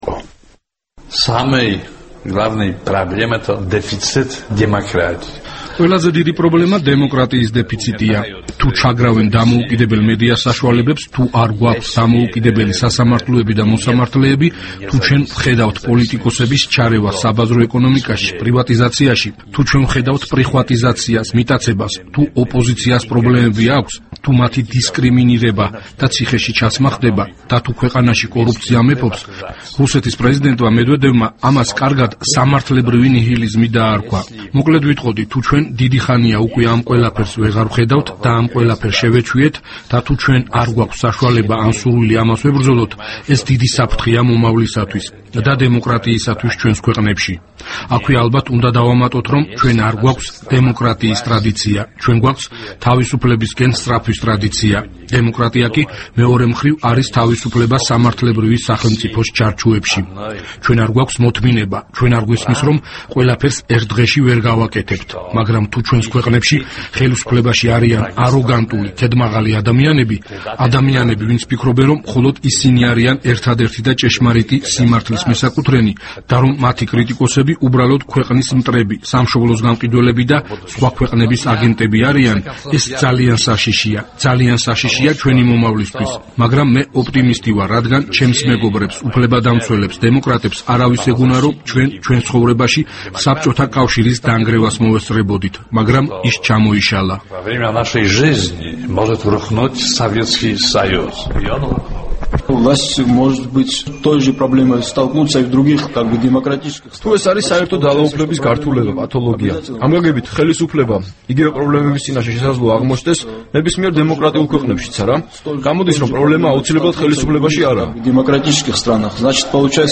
ინტერვიუ ადამ მიხნიკთან